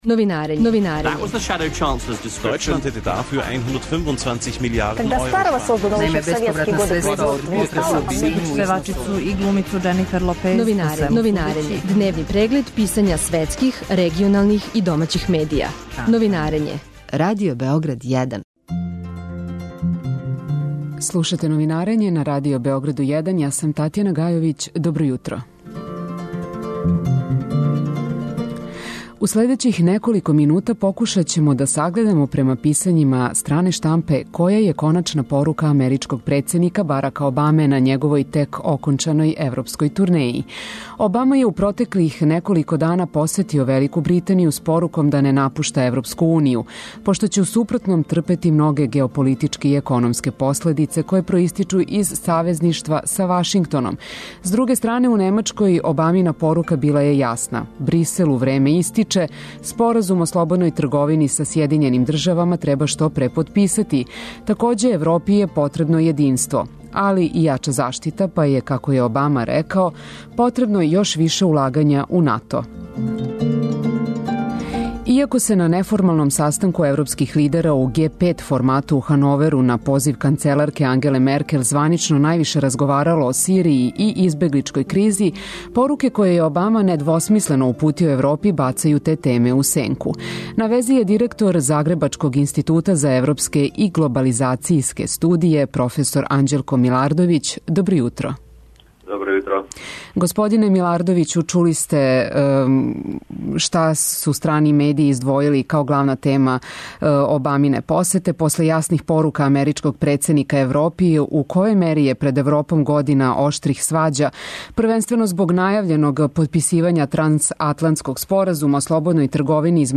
Гост емисије: загребачки професор